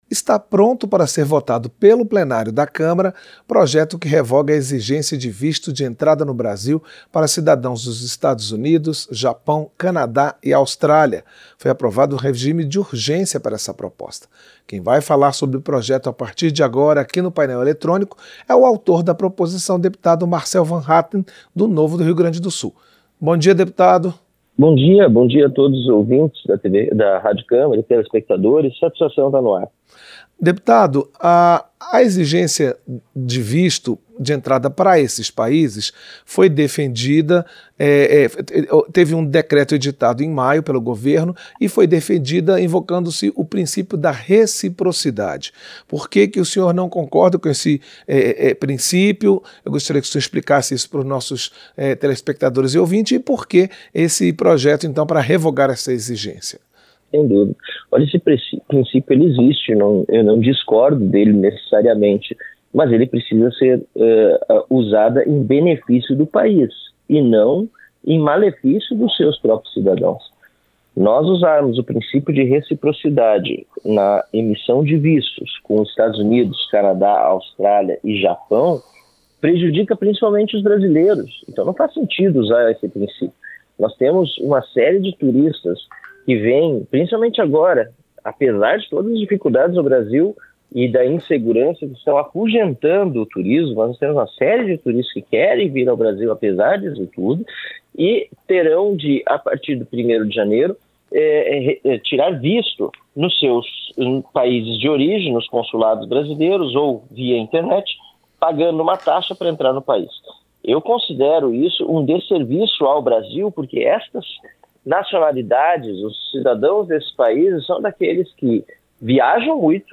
Entrevista - Dep. Marcel Van Hattem (Novo-RS)